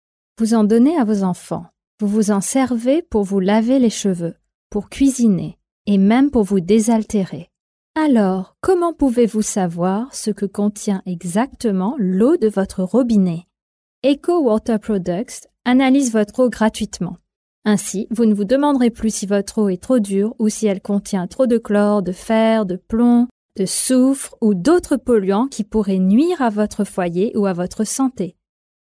French Female 03877
NARRATION
COMMERCIAL